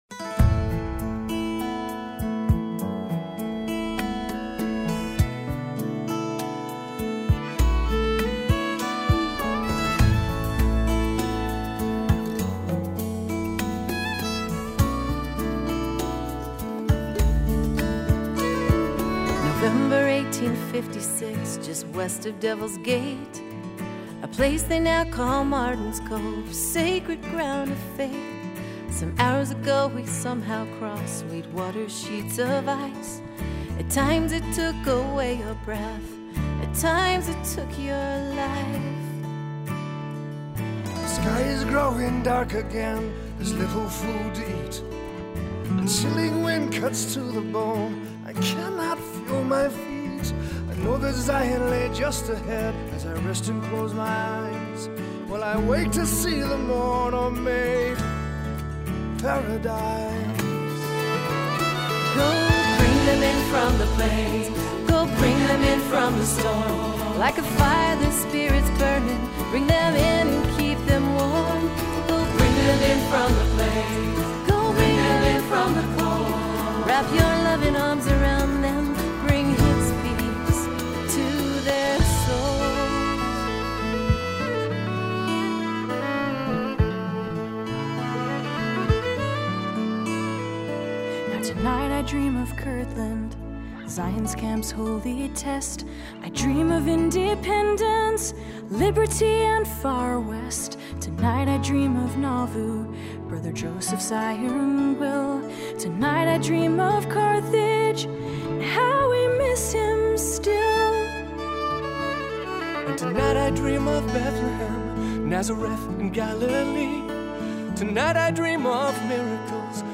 Our Reunion Fireside consisted of 4 short talks by youth trekkers, a special youth choir performance of our trek theme song:  Bring Them In
gobringtheminvocal.mp3